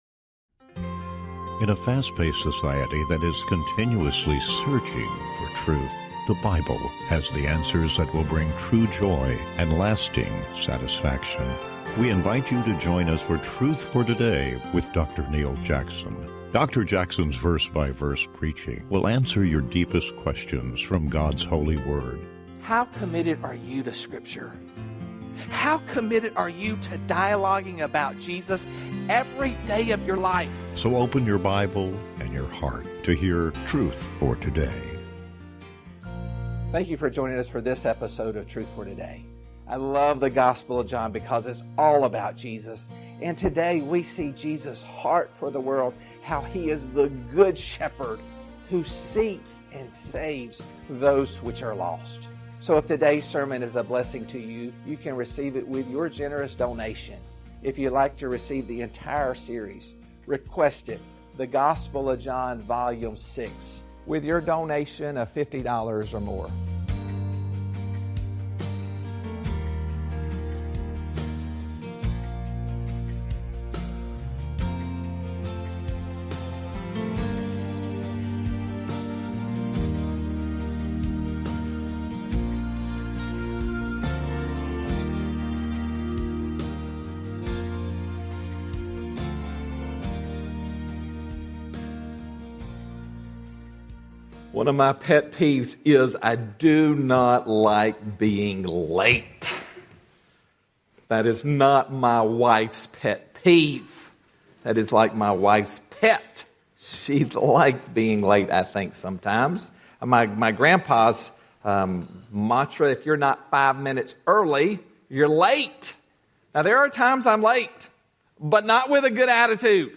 Truth for Today is committed to providing a broadcast that each week expounds God’s Word in a verse-by-verse chapter-by-chapter format.